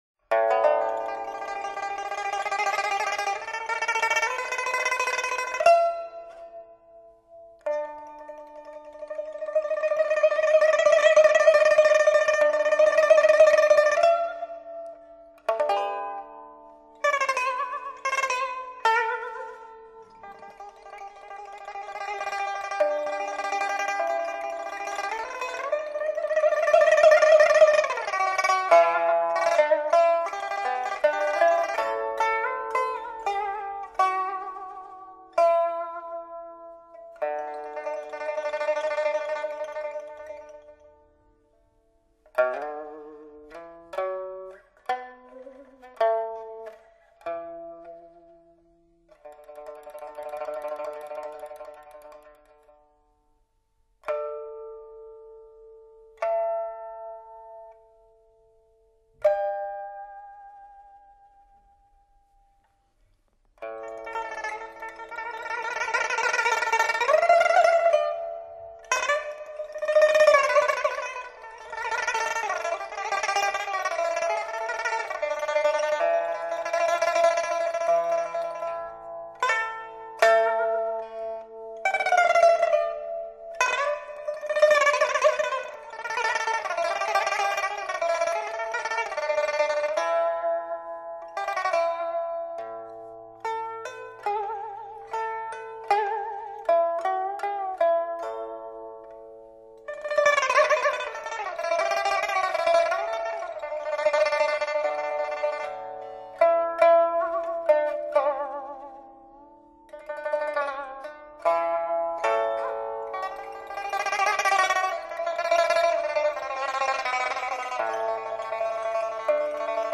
这套专辑录音质量极佳，可达至发烧的境界，异彩纷呈！